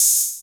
HH OPEN09.wav